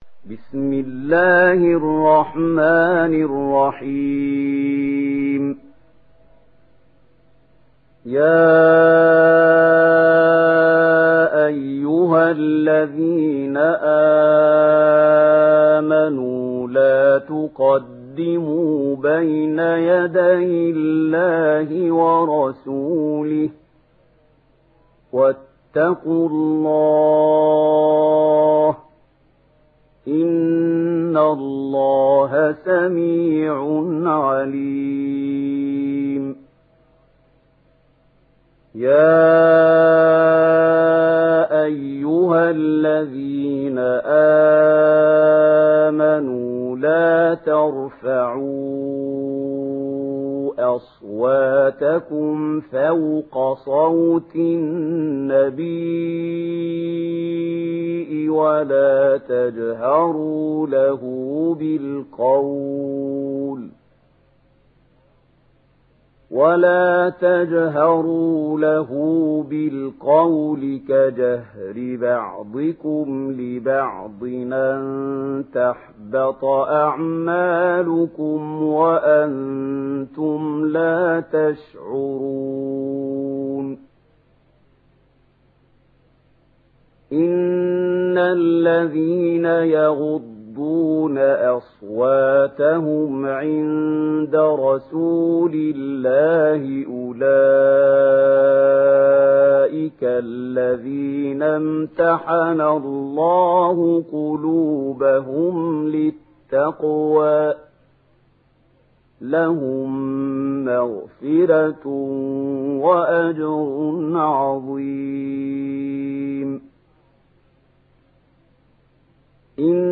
Surah الحجرات MP3 by محمود خليل الحصري in ورش عن نافع narration. Listen and download the full recitation in MP3 format via direct and fast links in multiple qualities to your mobile phone.
مرتل